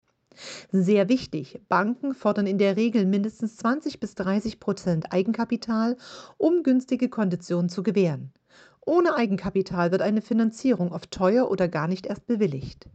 Sie ist Immobilienexpertin und Maklerin.